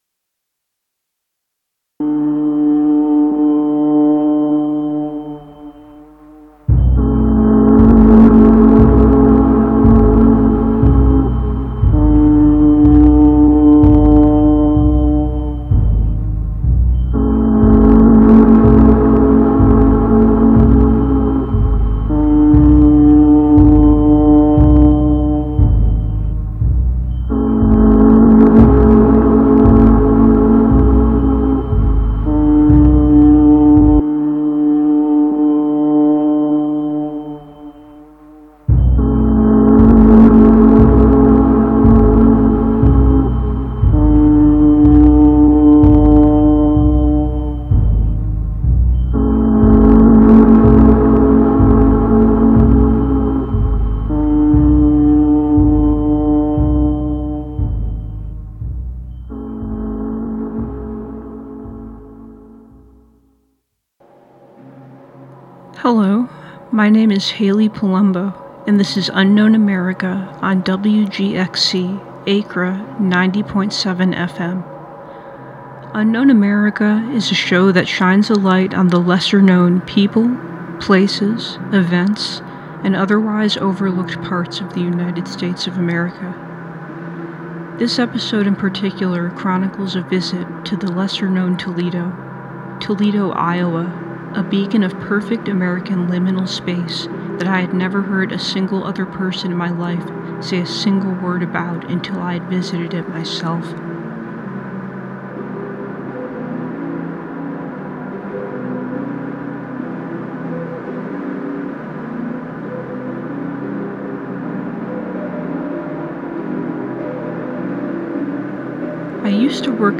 "Unknown America" is a show that dives into places, people, events, and other aspects of American history that often go overlooked. Through occasional interviews, on-site reporting, frantically obsessive research, and personal accounts, the listener will emerge out the other side just a bit wiser and more curious about the forgotten footnotes of history that make America fascinating, curious, and complicated.